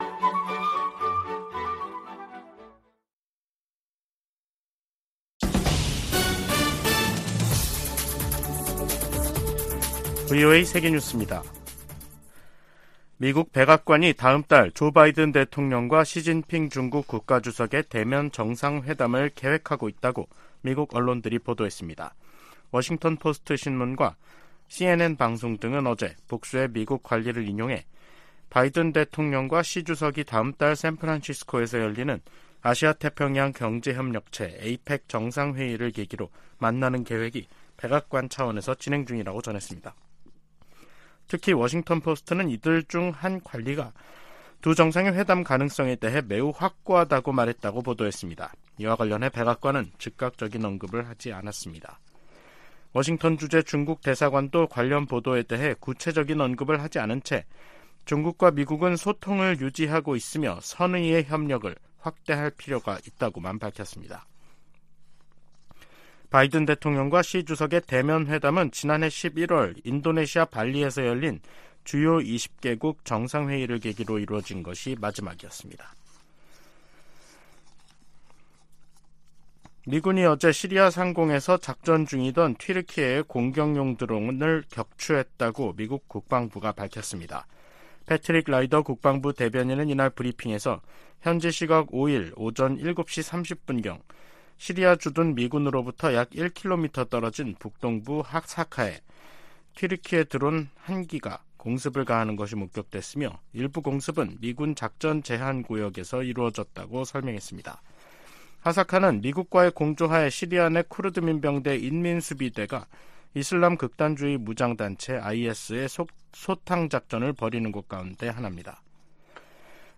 VOA 한국어 간판 뉴스 프로그램 '뉴스 투데이', 2023년 10월 6일 2부 방송입니다. 미 국방부는 최근 공개한 대량살상무기(WMD) 대응 전략을 정치·군사적 도발로 규정한 북한의 반발을 일축했습니다. 미국의 인도태평양 지역 동맹과 파트너십이 그 어느 때보다 강화되고 있으며, 미국의 가장 큰 전략적 이점 중 하나라고 미국 국방차관보가 말했습니다. 미 상원의원들이 올해 첫 한반도 안보 청문회에서 대북 정책을 실패로 규정하며 변화 필요성을 강조했습니다.